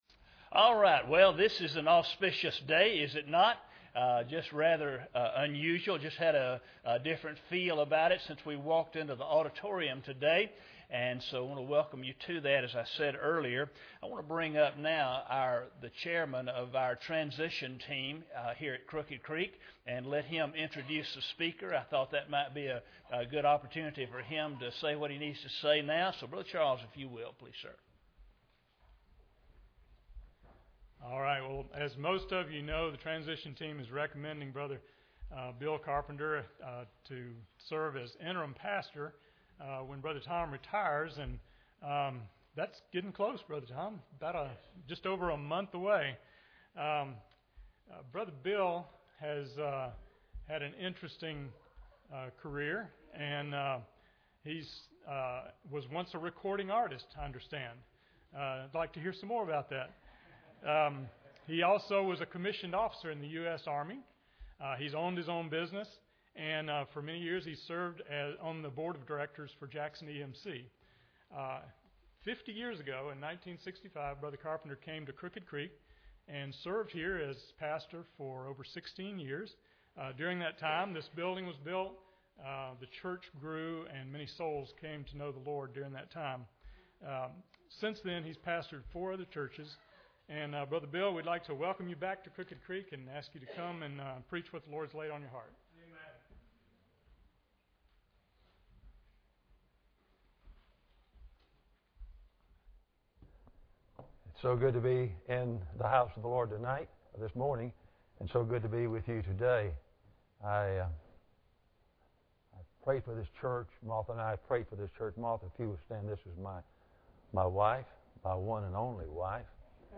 John 10:11 Service Type: Sunday Morning Bible Text